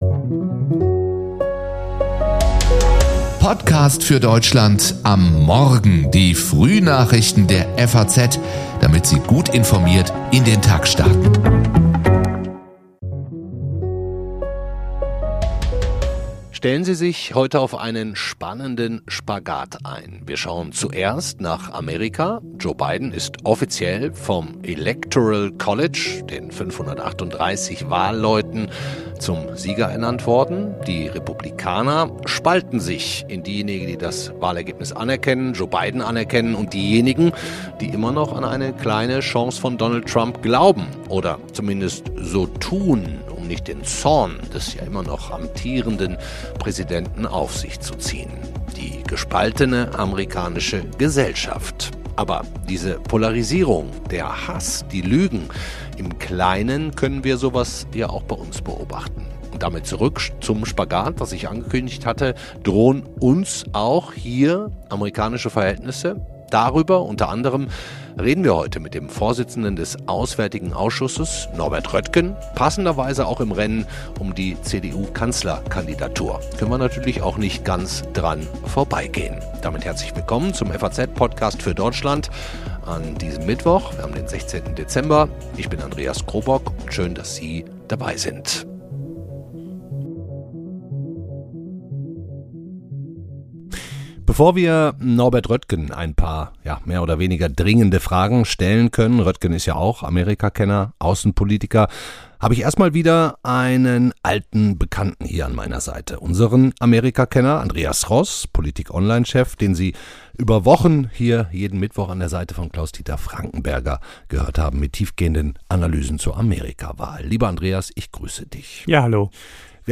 Darüber reden wir heute mit dem Vorsitzenden des Auswärtigen Ausschusses Norbert Röttgen, der im Rennen um die CDU-Kanzlerkandidatur derzeit in den Umfragen zulegt.